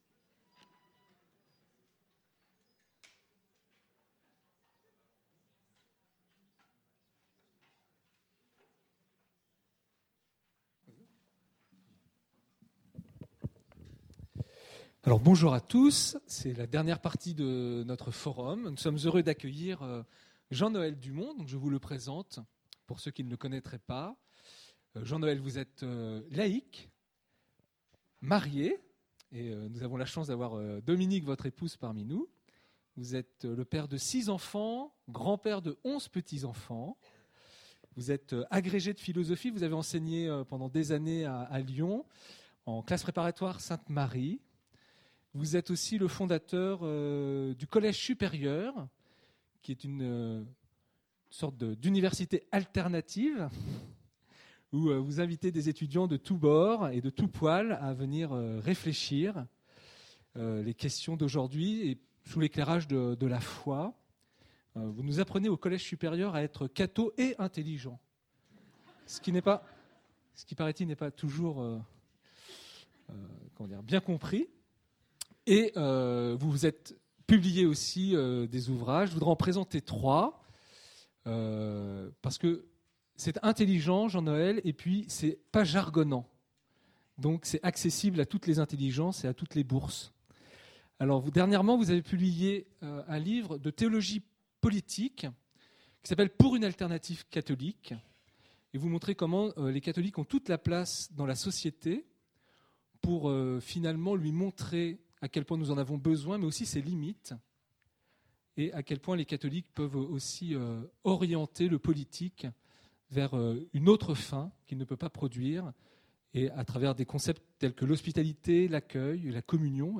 Conférence : Que veut dire "être chrétien jeune"?